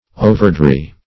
Overdry \O`ver*dry"\